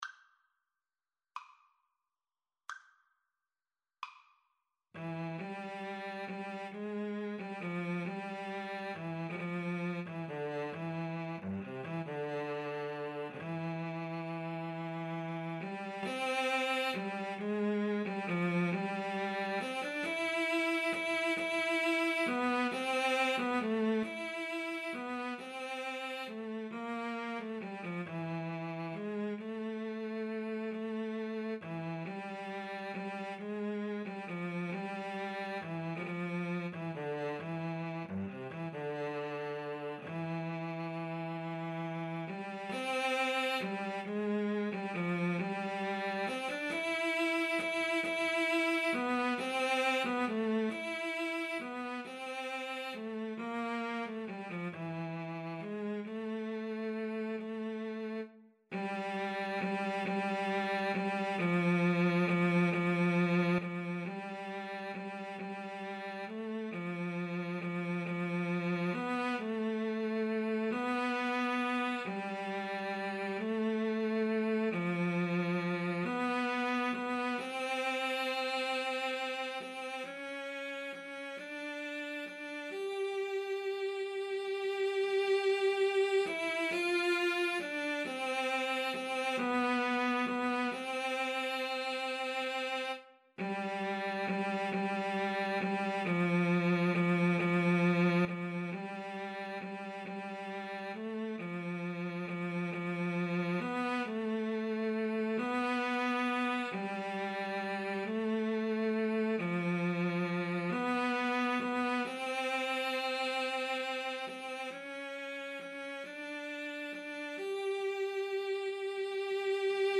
Andantino .=c.45 (View more music marked Andantino)
6/8 (View more 6/8 Music)
Cello Duet  (View more Intermediate Cello Duet Music)
Classical (View more Classical Cello Duet Music)